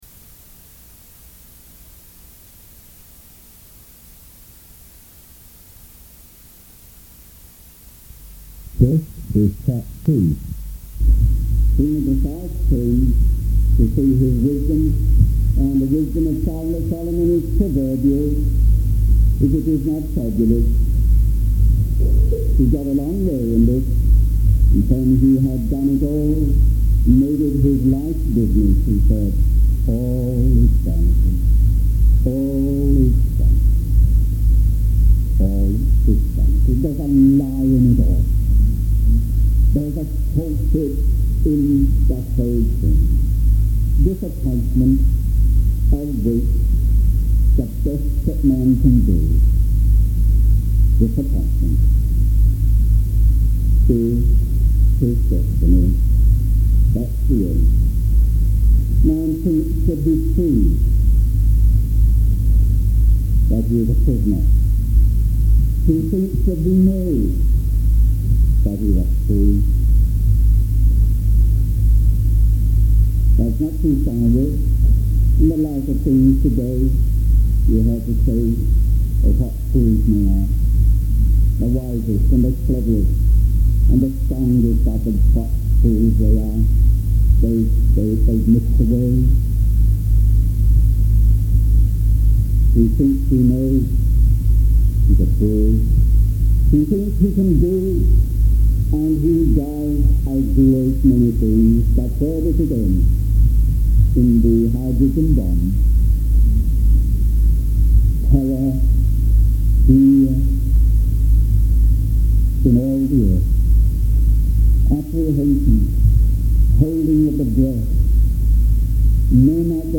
The sermon offers deep encouragement to the church to stand firm in truth and look forward to Christ's ultimate victory and judgment.